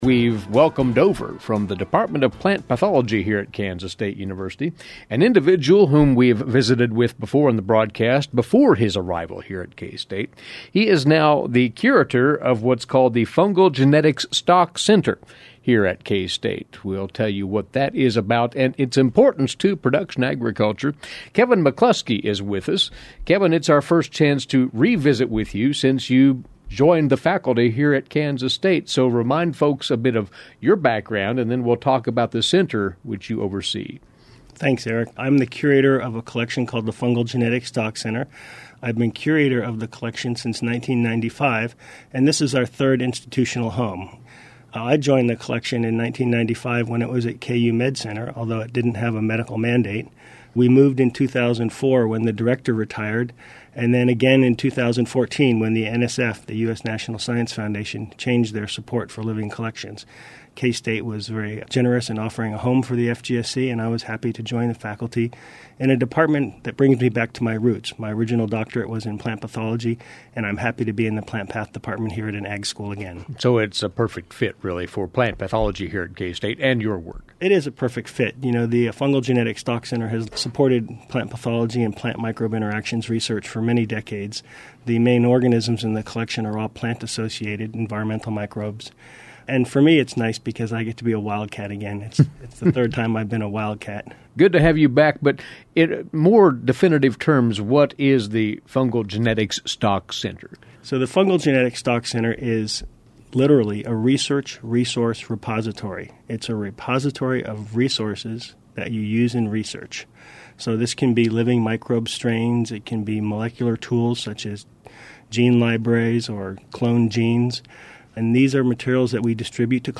FGSC Publicity over the years FGSC Publicity over the years Fungal Genetics Stock Center video FGSC featured in 2017 K-State Research Magazine 2016 Interview by Kansas Ag Radio Return to he FGSC home page 3/18